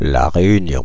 Ääntäminen
Ääntäminen France (Île-de-France): IPA: [la ʁe.y.njɔ̃] Haettu sana löytyi näillä lähdekielillä: ranska Käännöksiä ei löytynyt valitulle kohdekielelle.